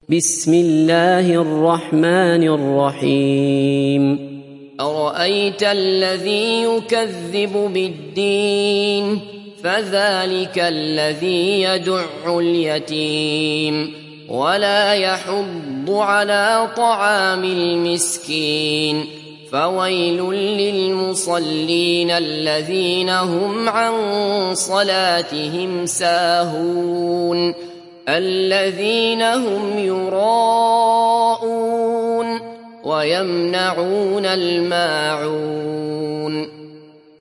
تحميل سورة الماعون mp3 بصوت عبد الله بصفر برواية حفص عن عاصم, تحميل استماع القرآن الكريم على الجوال mp3 كاملا بروابط مباشرة وسريعة